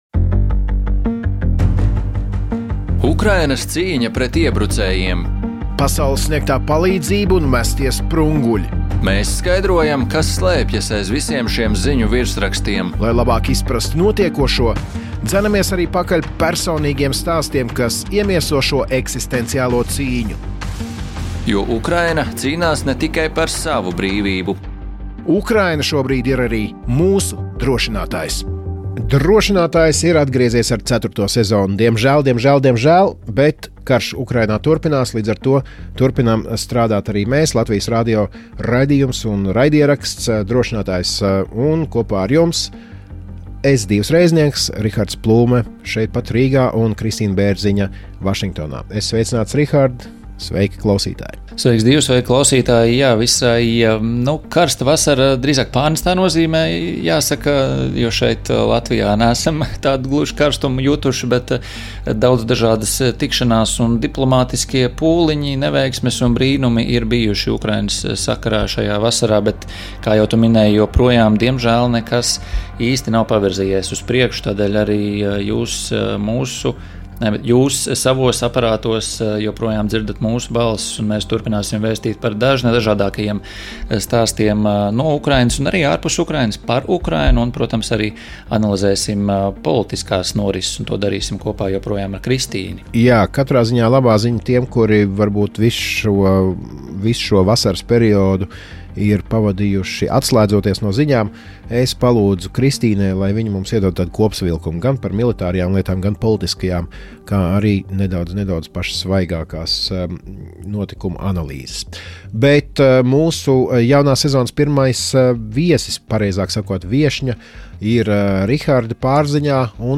Toto je kompletní rozhovor bez překladu z podcastu Lotyšského rozhlasu „Drošinātājs“ („Pojistka“) věnovaného lidu Ukrajiny a jeho boji proti ruské agresi.